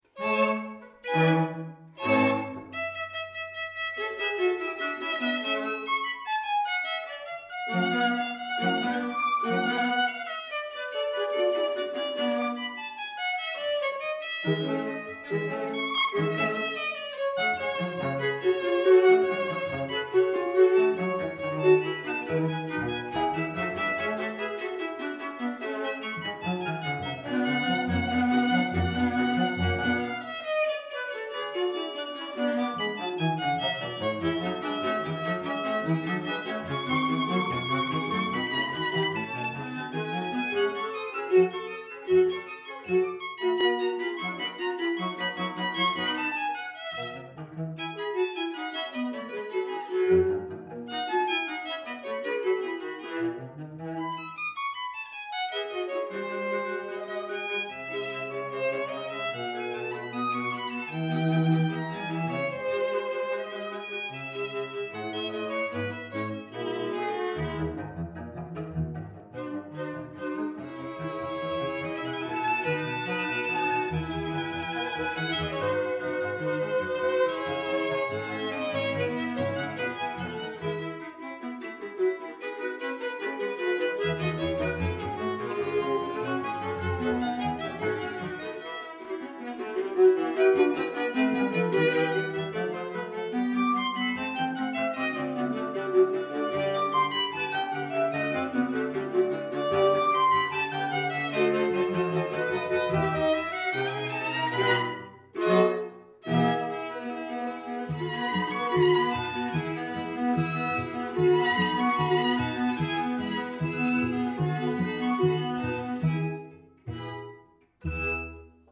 Soundbite 4th Movt